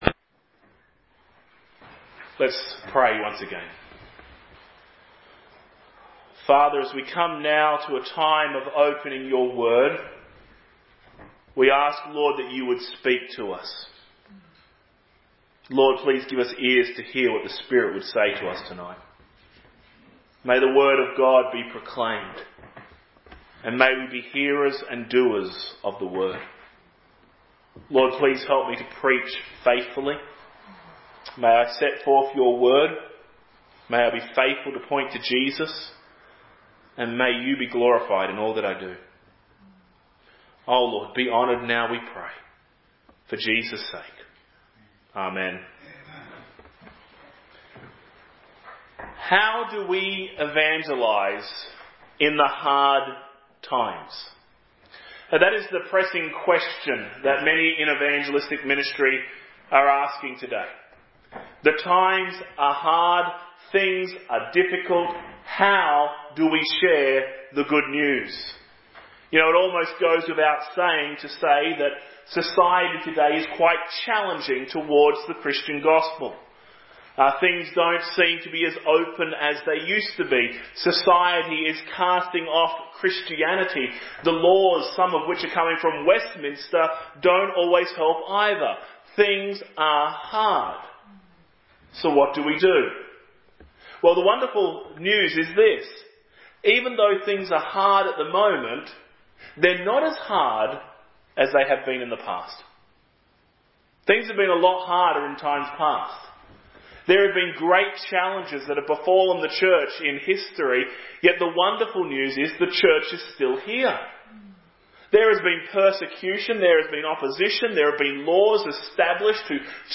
Evening Sermon